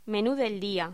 Locución: Menú del día
voz
Sonidos: Hostelería